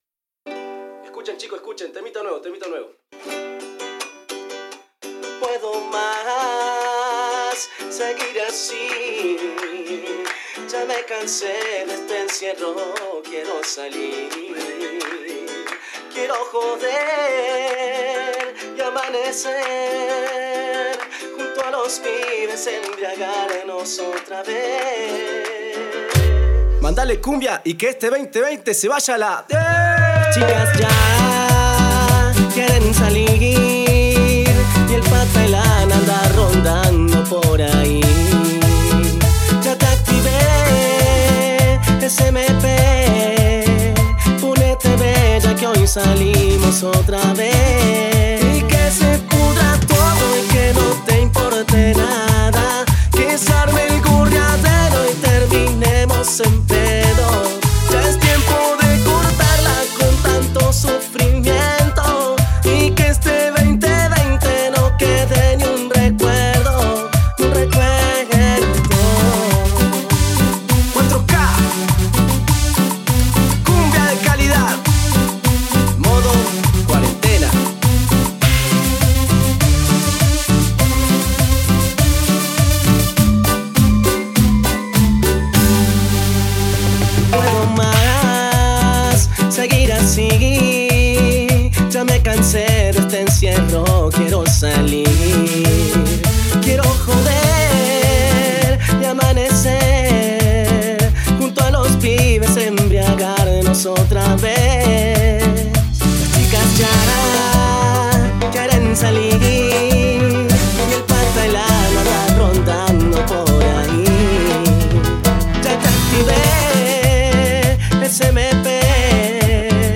Genero Cumbia